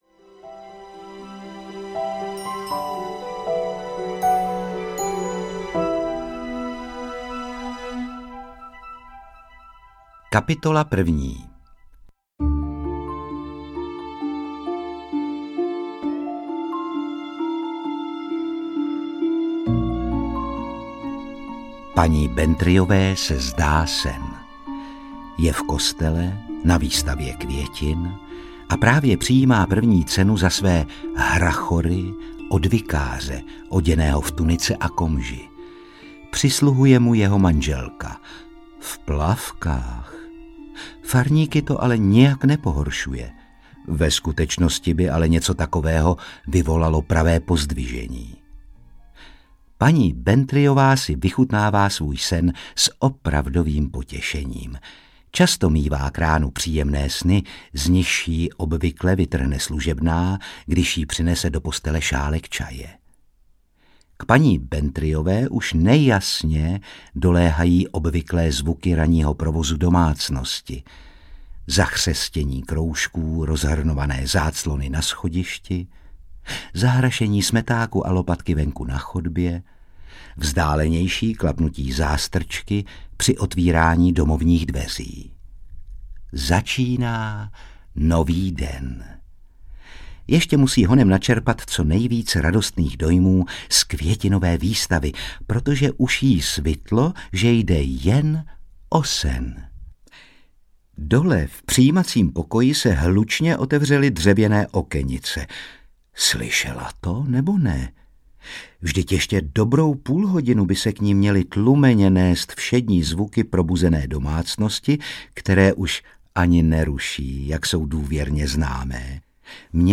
Mrtvá v knihovně audiokniha
Ukázka z knihy
• InterpretRůžena Merunková, Otakar Brousek ml.